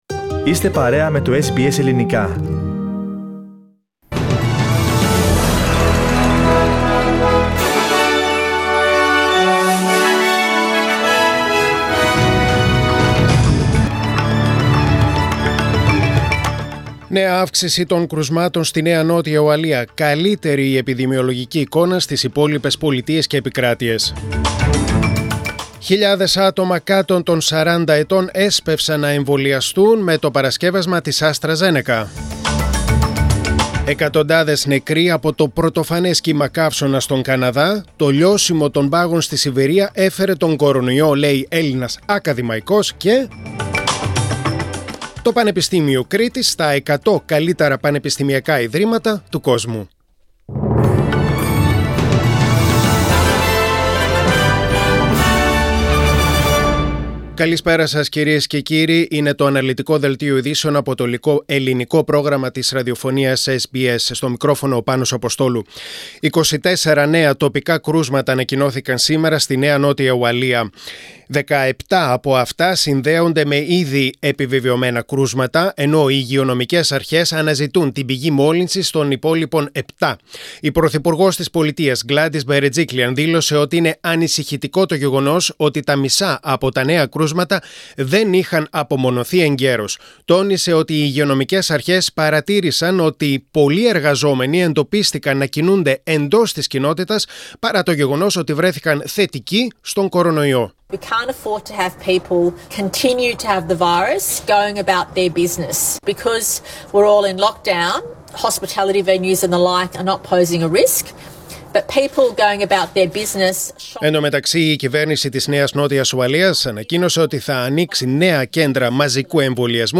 SBS Greek Bulletin.